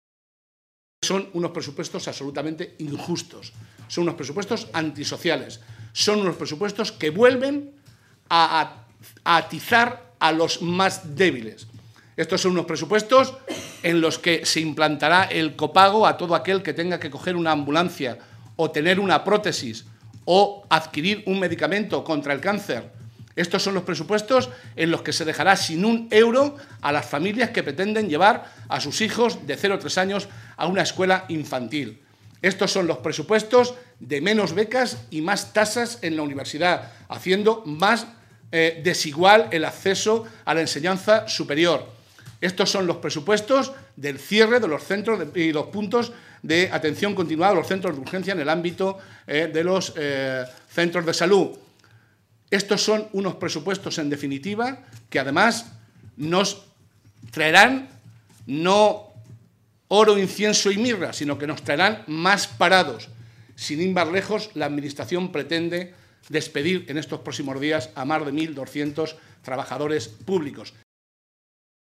Molina, que ofreció una rueda de prensa momentos antes del inicio del pleno que se desarrollará hoy y mañana en la cámara regional, indicó que lo más grave de las cuentas para el próximo año es que “son totalmente injustas, antisociales y vuelven a atacar a los más débiles”.